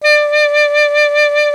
55af-sax09-D4.aif